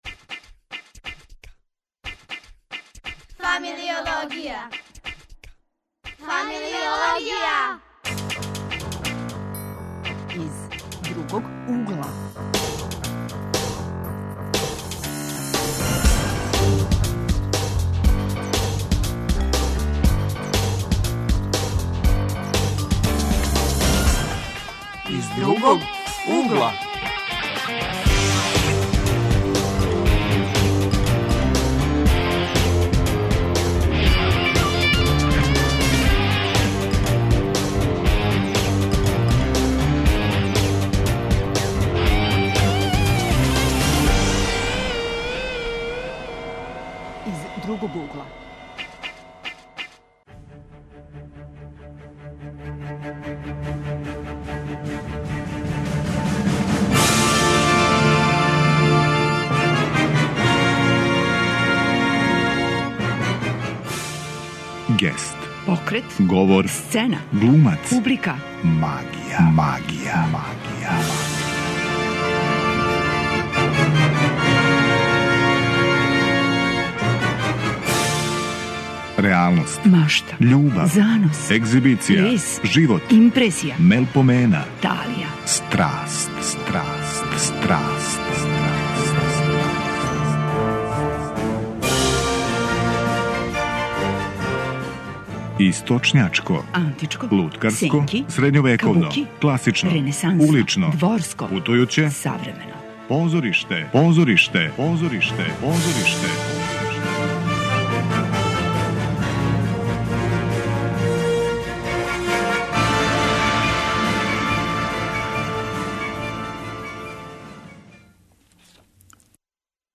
Одрастање уз или без позоришта тема је емисије 'Из другог угла'. Гости су млади љубитељи и глумци у аматерским позориштима.